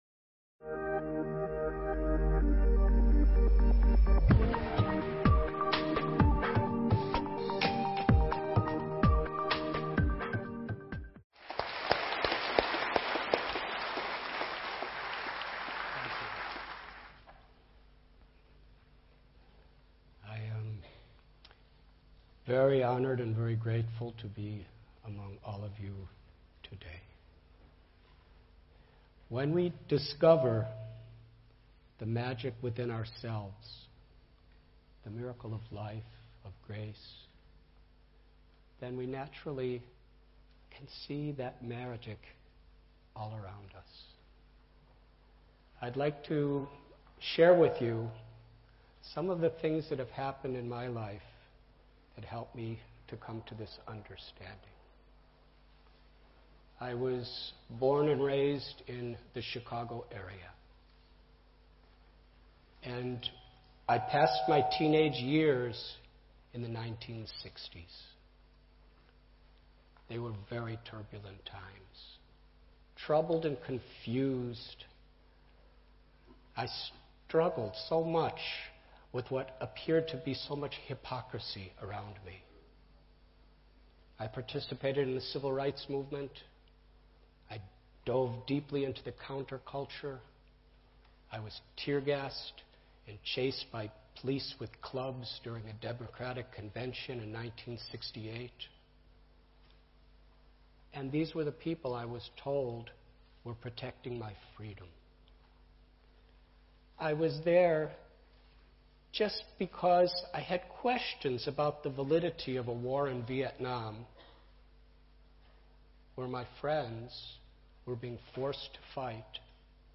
Here is the full transcript of Spiritual Teachings by Bhakti Yoga practitioner, Radhanath Swami at TEDxLondonBusinessSchool.